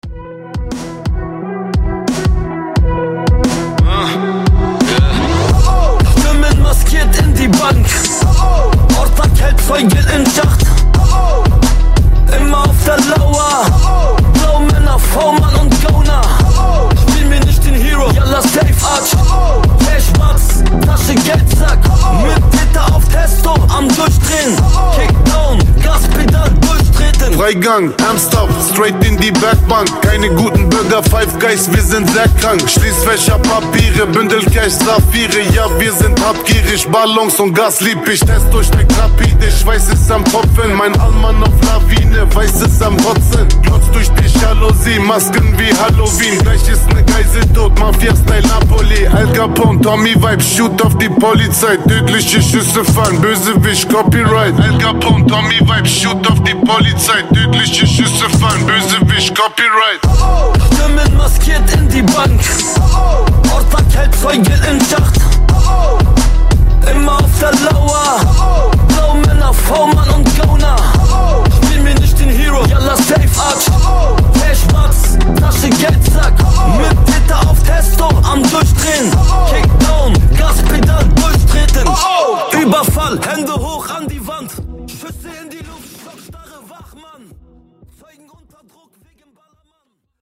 Genres: RE-DRUM , TOP40
Clean BPM: 80 Time